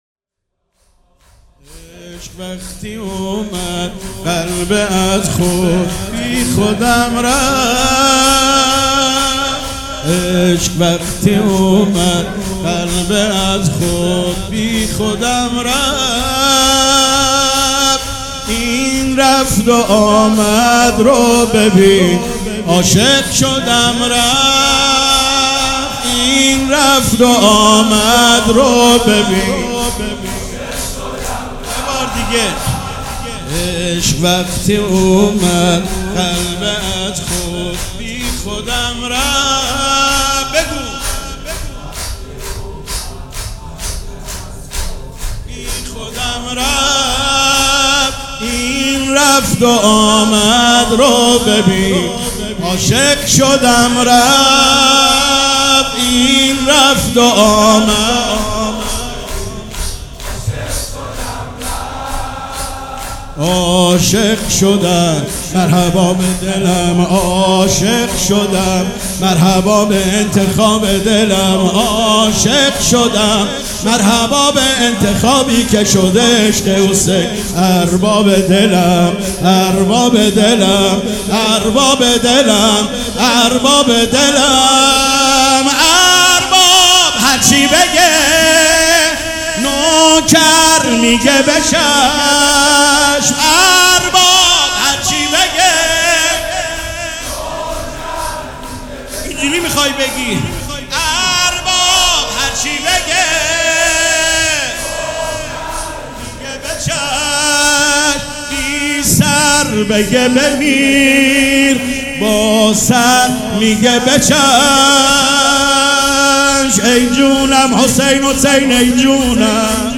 شور
سبک اثــر شور
مراسم عزاداری شب سوم